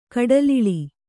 ♪ kaḍaliḷi